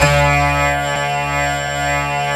SI2 TABLAS02.wav